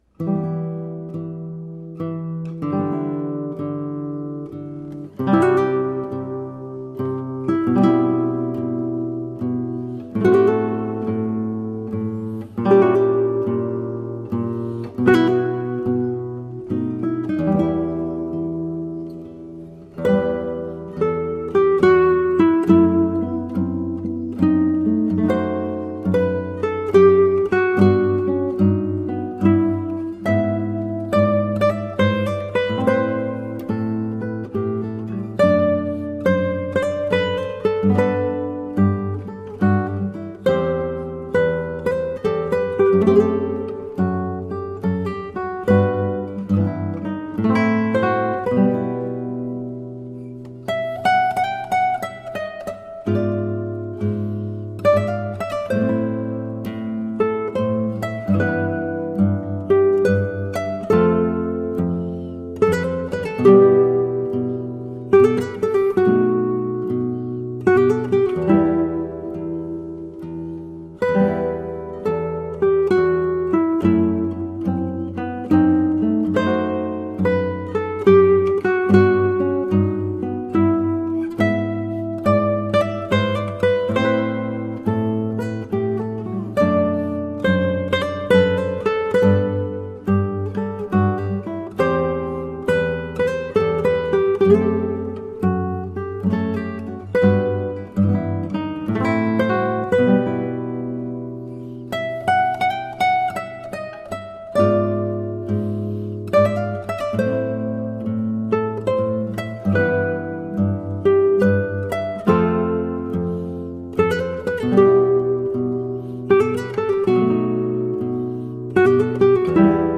albinoni-adagio-in-g-minor-guitar_arrgt.mp3